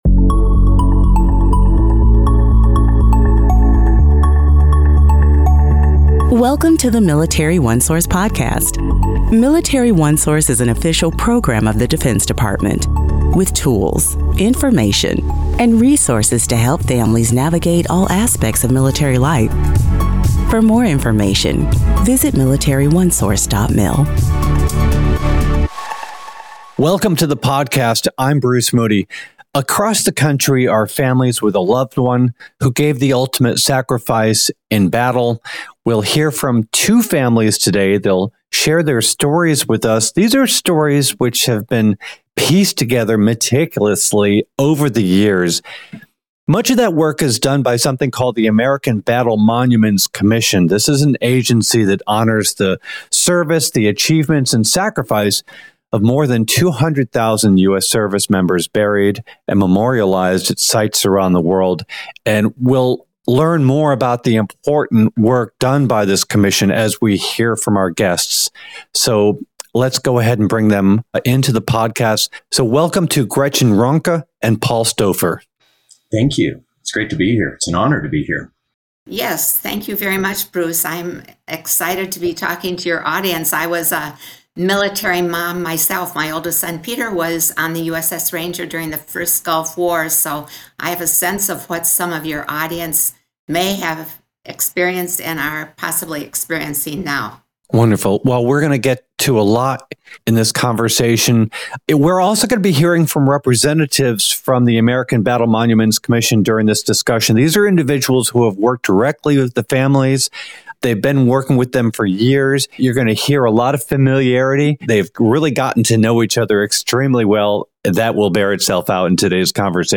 Listen to family members tell the story of military heroes killed in battle during WWI and WWII, memorializing service members interred overseas.